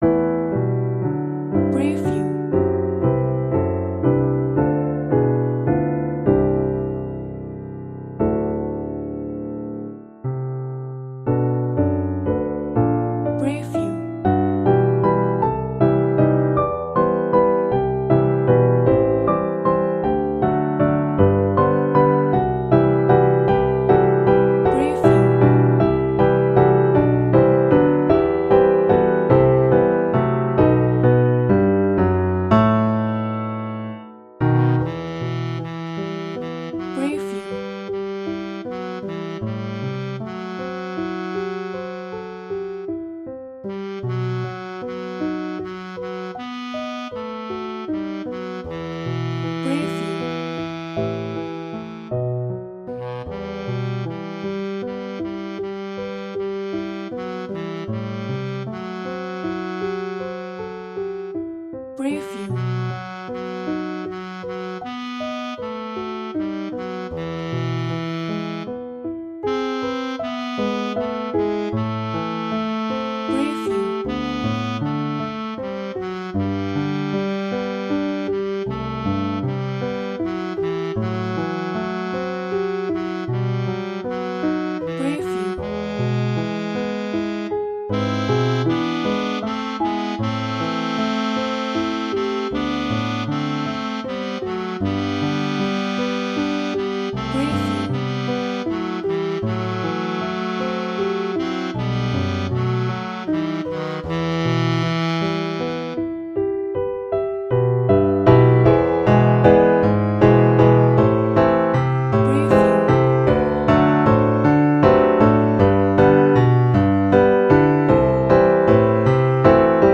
Тип: з супроводом Вид хору: TTBB Жанр: пісня К-сть сторінок